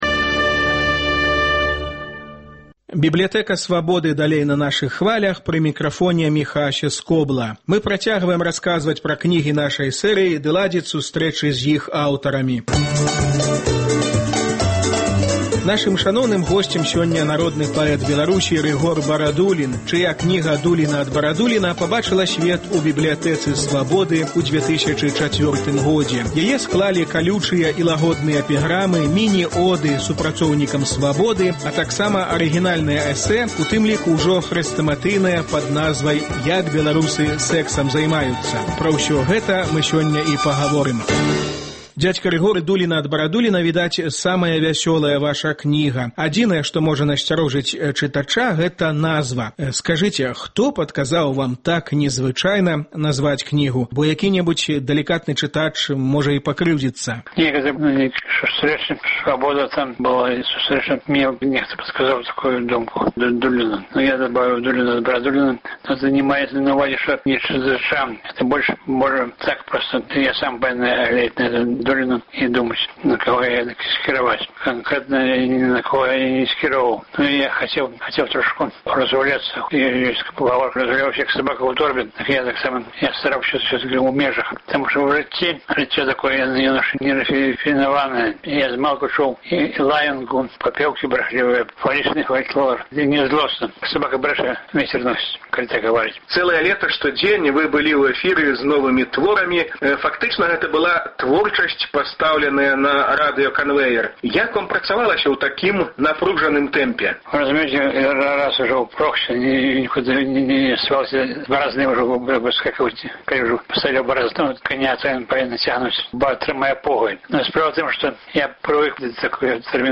У перадачы гучаць архіўныя запісы.